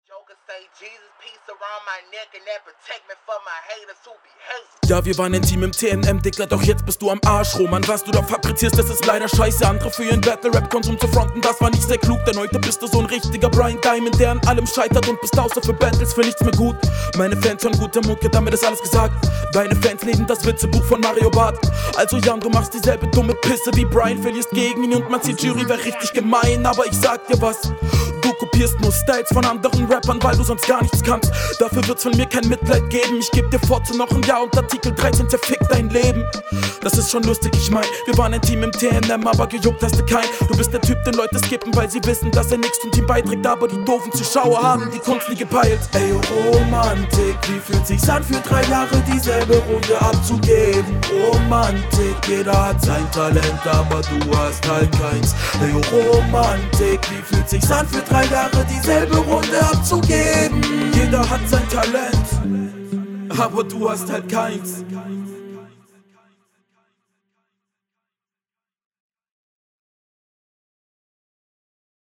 Dein Stimmeinsatz geht mir extrem gut rein, guuute Mischung aus chillig/arrogant und aggressiv!
Flowtechnisch mehr als solide.
Beat gefällt mir sehr gut.